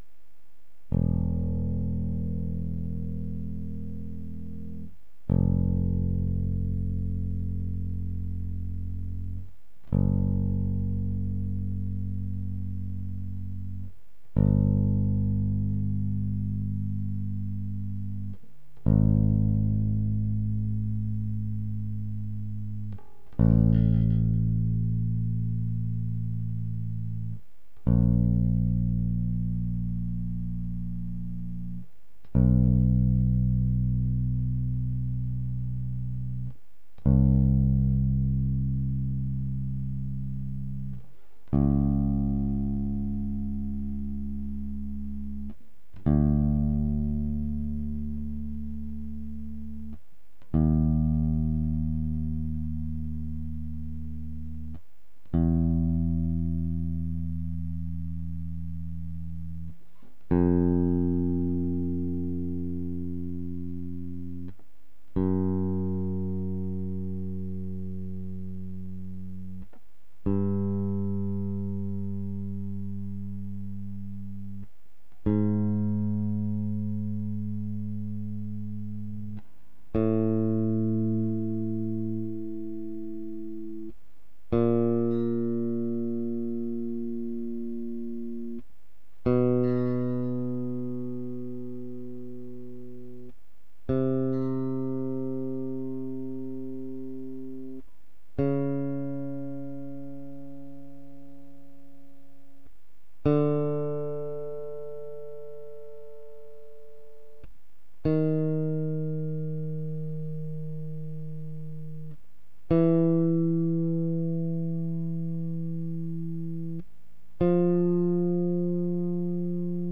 Index of /cubase/AIF_FILE/BUILDERS/BASSGUIT/FINGER